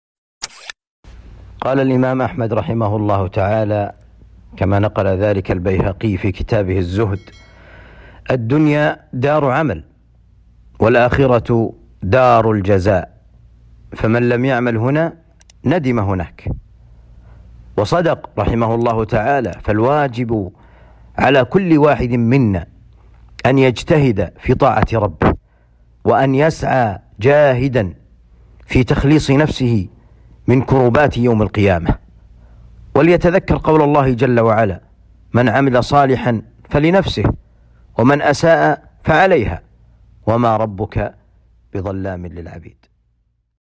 موعظة بليغة